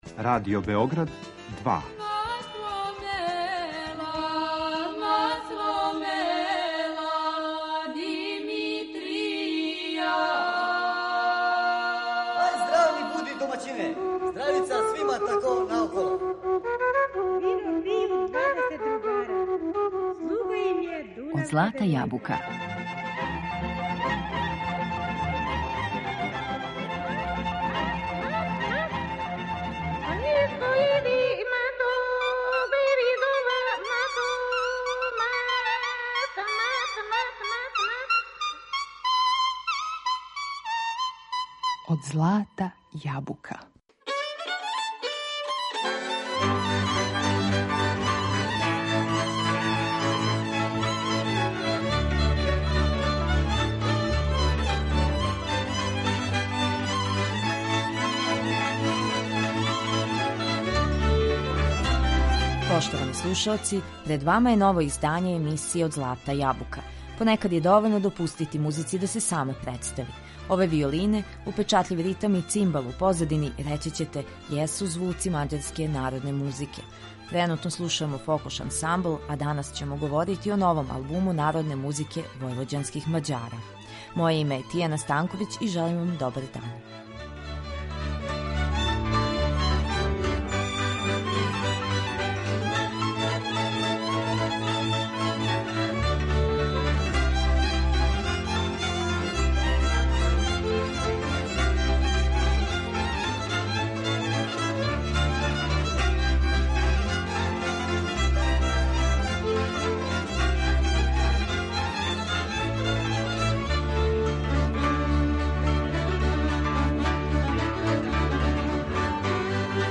На албуму се налази четрнаест приповедања, народних песама и инструменталних мелодија војвођанских Мађара, компактно уклопљених у нешто више од 43 минута.